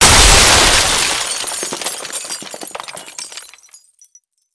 brute_impact_hev2.wav